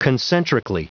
Prononciation du mot concentrically en anglais (fichier audio)
concentrically.wav